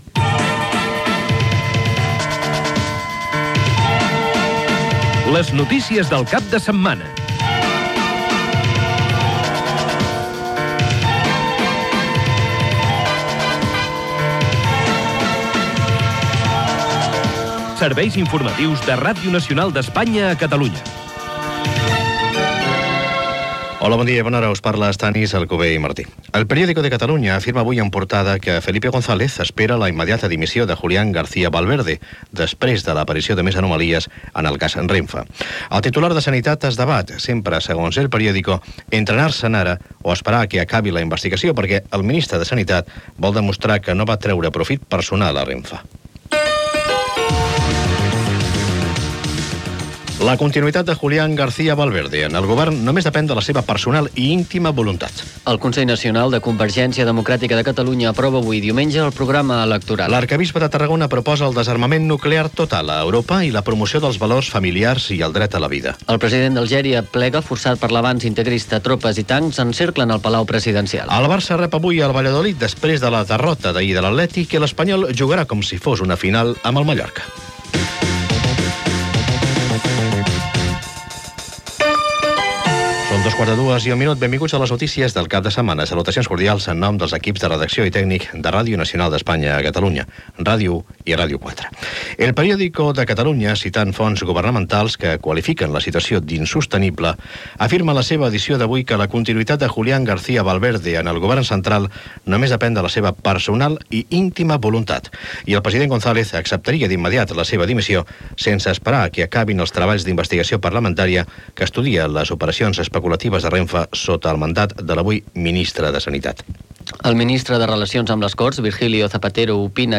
Careta de l'informatiu. Sumari informatiu: cas RENFE, Congrés de Convergència i Unió, etc.
Informatiu